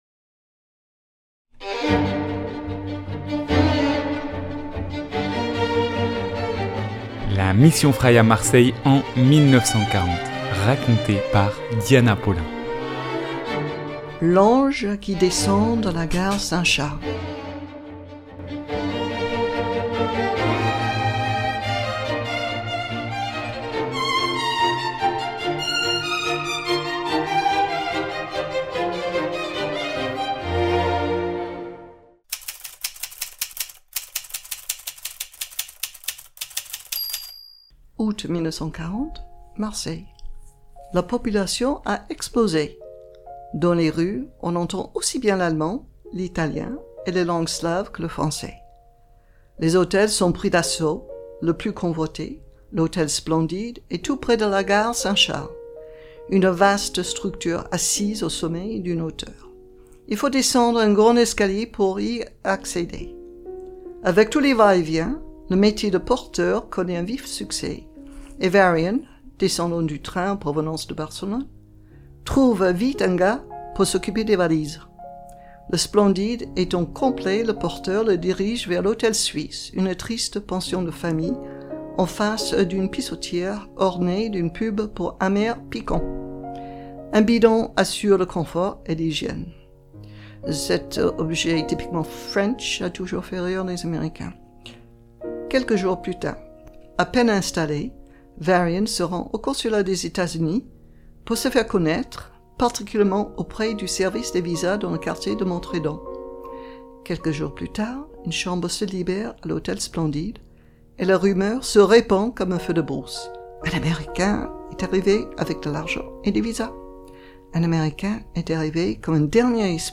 4 - L'ange qui descend de la gare saint charles (version piano).mp3 (2.26 Mo)